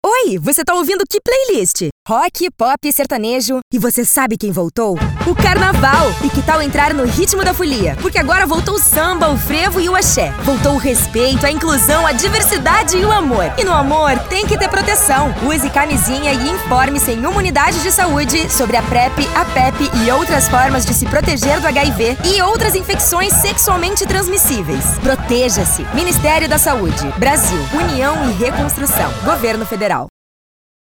Spot - Campanha de Prevenção às IST - Carnaval - MP3